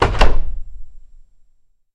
Doors-Wood
Apartment Door Slam, Solid Wooden